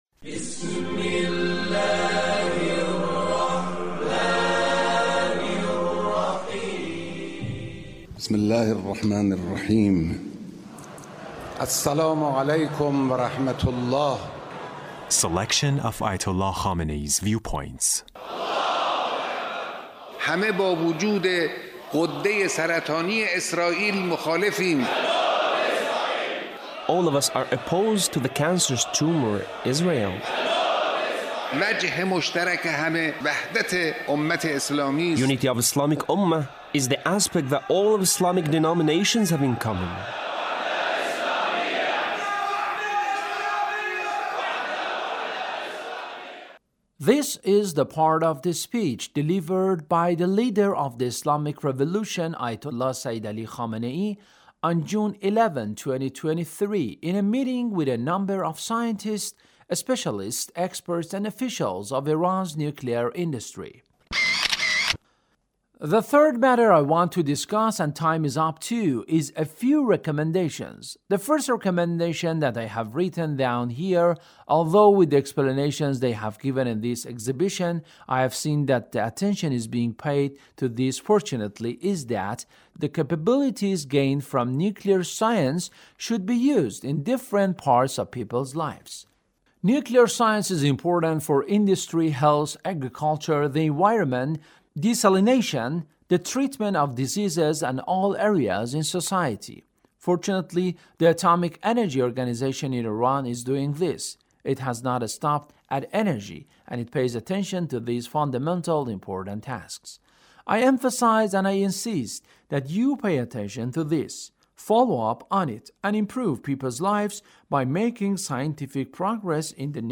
Leader's Speech in a meeting with a number of scientists,and officials of Iran’s nuclear industry